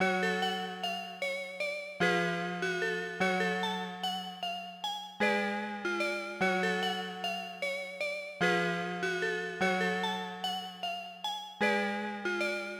150BPM travis scott type melody.wav